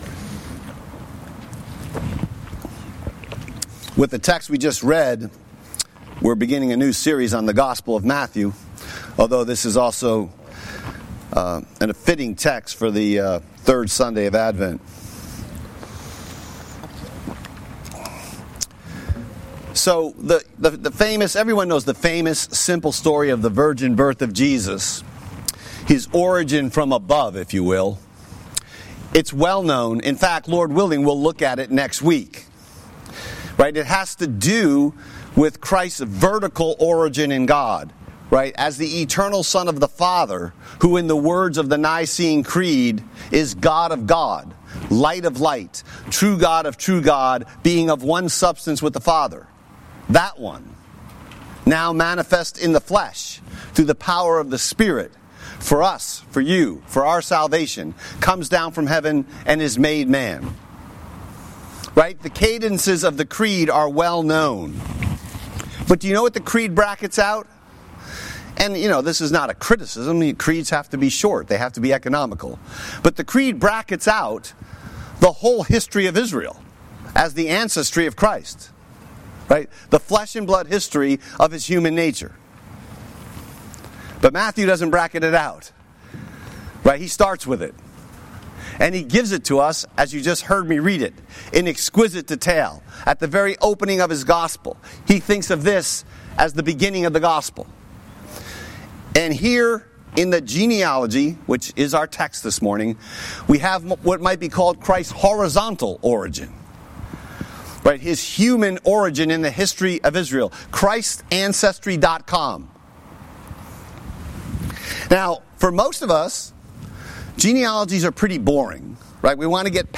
Sermon Text: Matthew 1:1-17